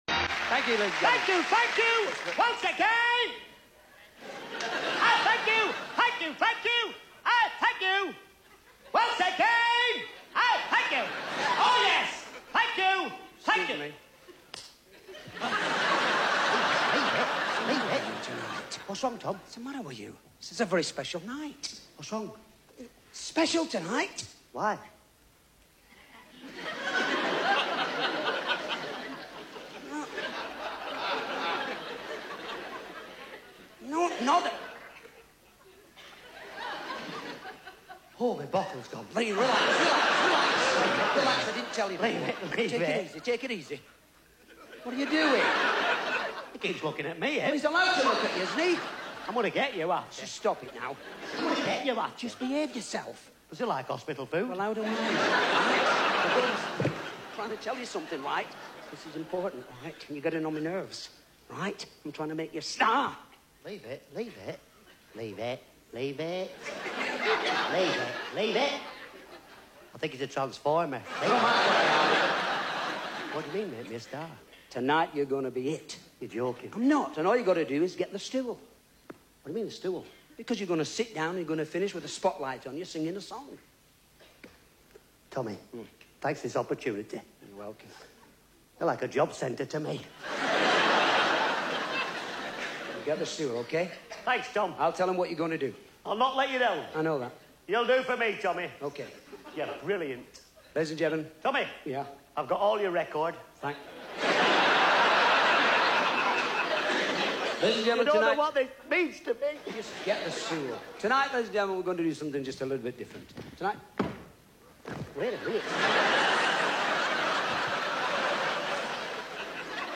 Family Favourites At The London Palladium.. A Brilliant Performance From The Great Comedy Duo..!